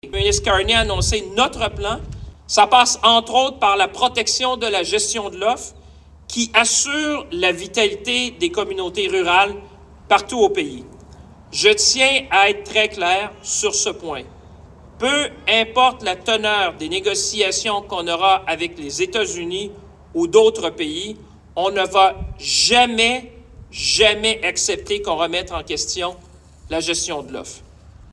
En point de presse à la ferme laitière Julio de Granby, François-Philippe Champagne a rappelé que les libéraux s’étaient engagés à protéger à tout prix la gestion de l’offre.
François-Philippe Champagne explique :